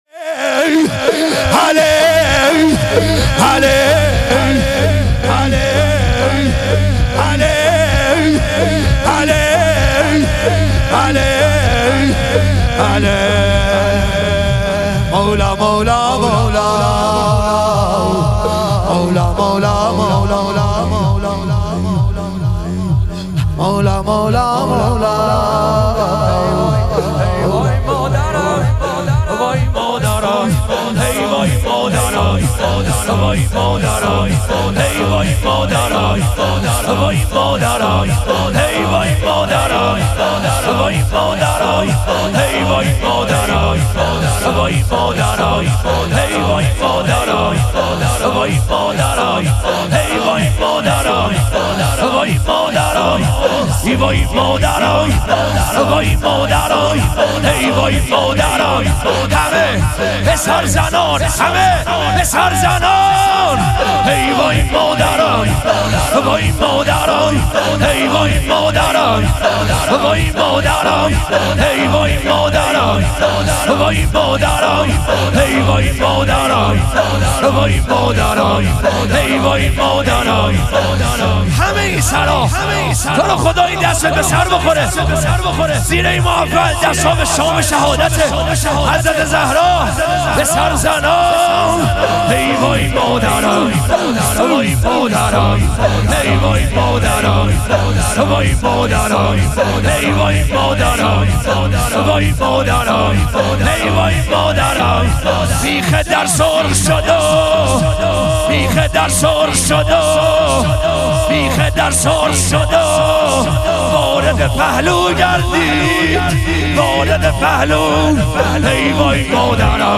شام غریبان حضرت زهرا علیها سلام - لطمه زنی